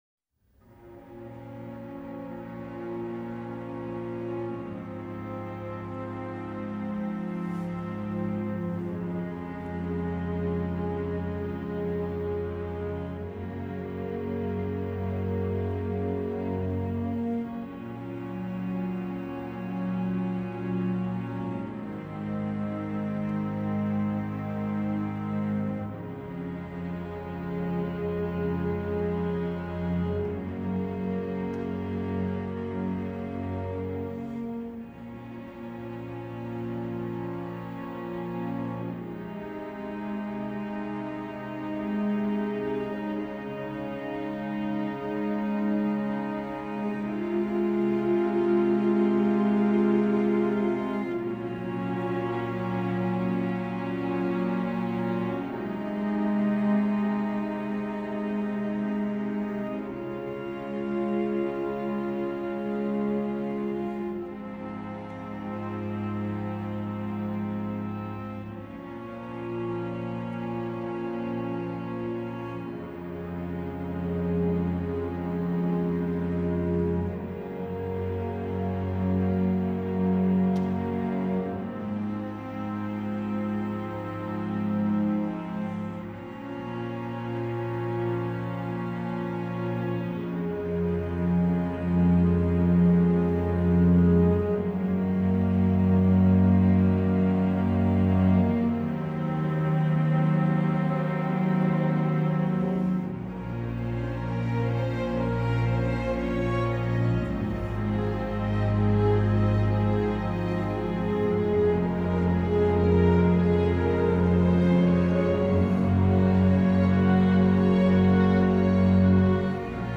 Enjoy the instrumental song below……